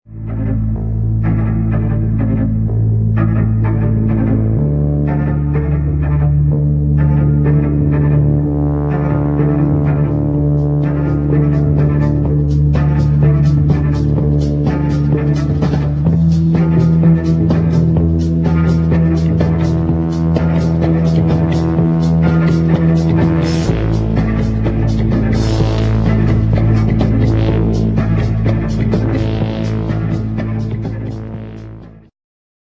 1980 thrilling fast instr.